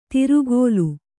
♪ tirugōlu